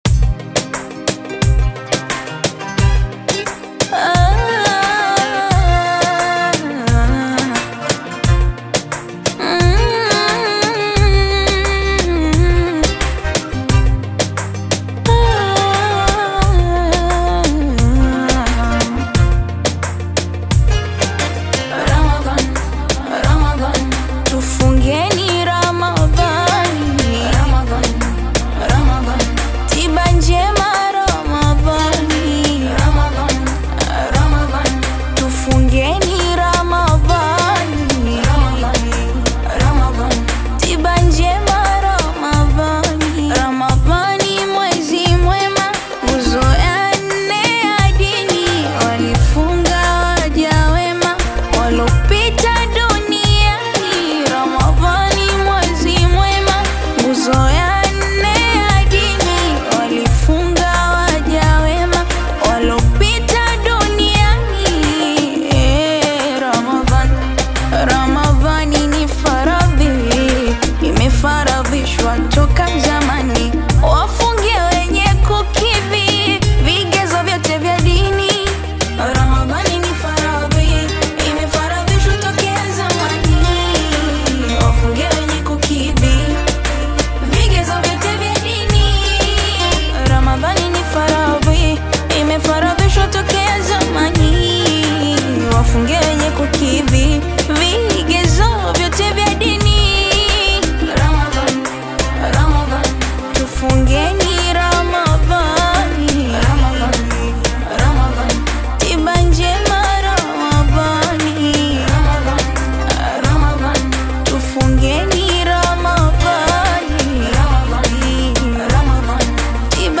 AudioQaswida
Taarab/Pop single